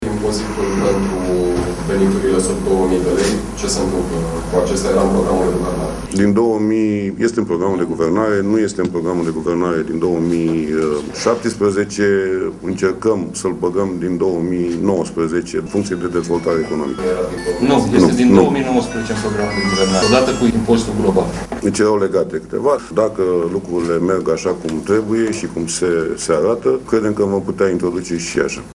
Iată dialogul de vineri, de la Deva, în care intervine și vicepremierul Marcel Ciolacu: